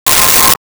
Sci Fi Beep 04
Sci Fi Beep 04.wav